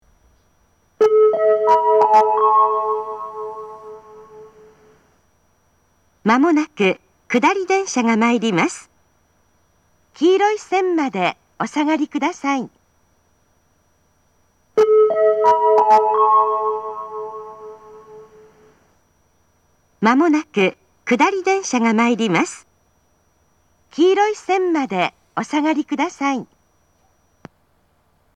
自動放送
仙石旧型（女性）
接近放送
仙石旧型女性の接近放送です。同じ内容を2度繰り返します。
Atsugi-D-Sekkin.mp3